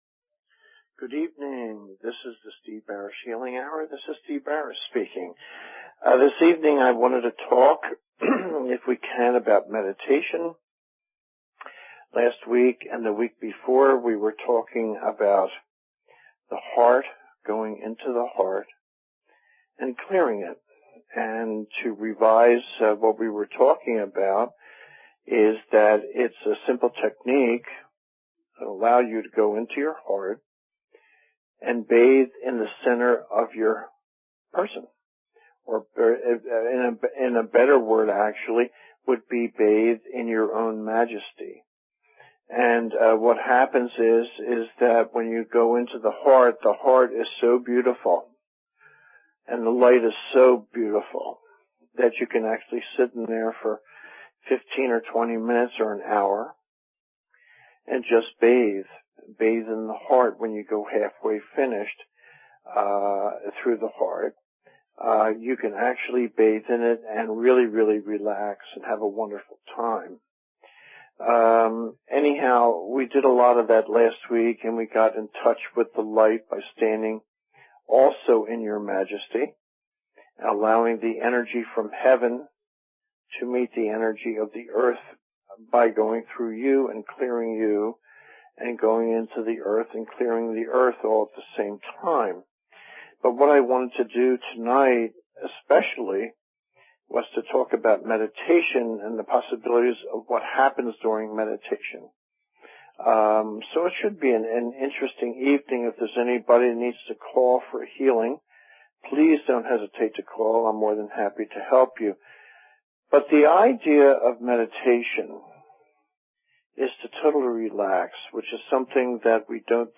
Talk Show Episode, Audio Podcast, The_Healing_Hour and Courtesy of BBS Radio on , show guests , about , categorized as